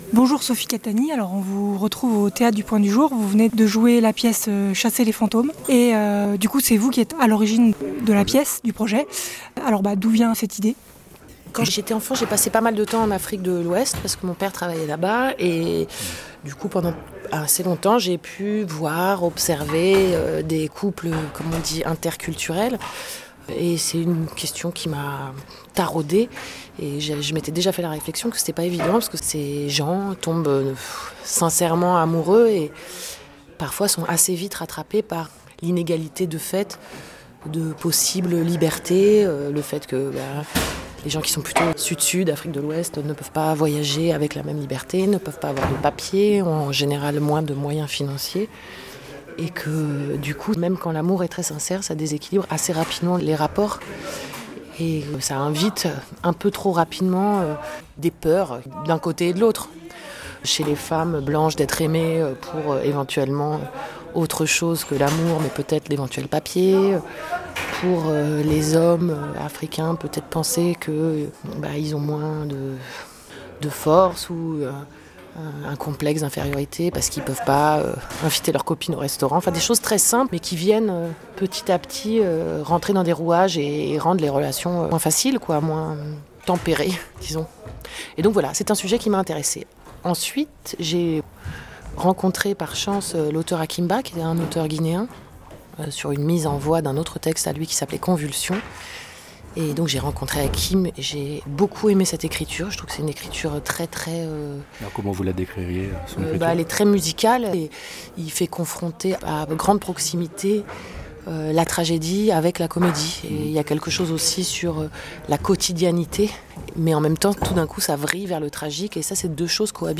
Entretien à l'issue de la représentation